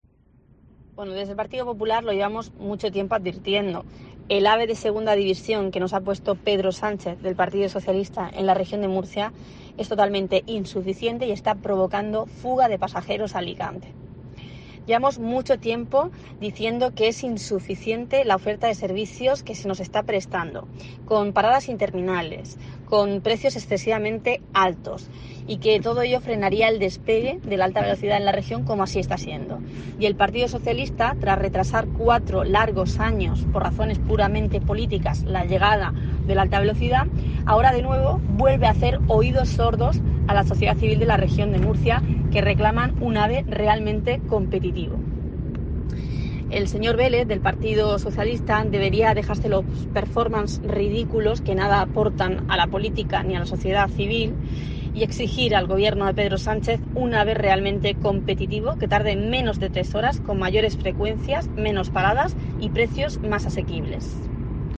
Miriam Guardiola, portavoz del Partido Popular en la Región de Murcia